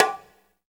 PRC BONGO 7.wav